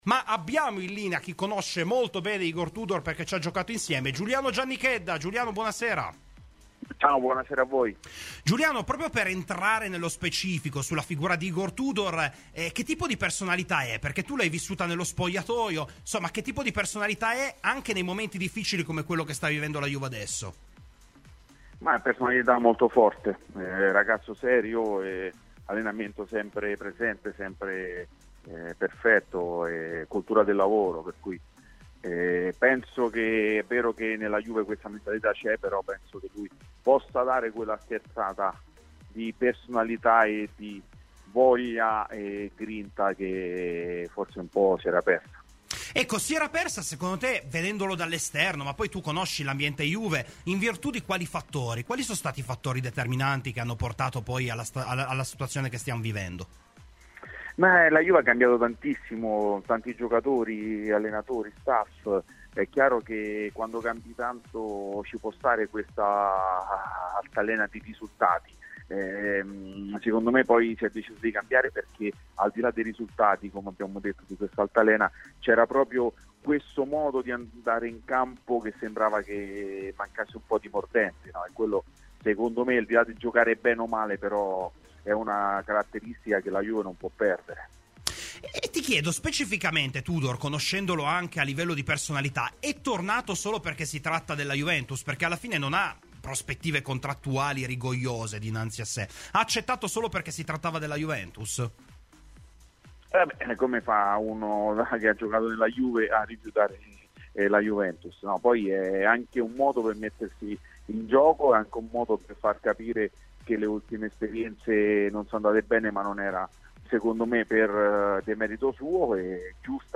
Ospite di "Domenica Sport" su Radio Bianconera, l'ex centrocampista della Juventus Giuliano Giannichedda ha parlato dell'Igor Tudor giocatore, che lui ha vissuto nel corso della propria esperienza in bianconero: "Una personalità molto forte, era un ragazzo serio e dalla grande cultura del lavoro.